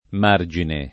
margine [ m # r J ine ] s. m.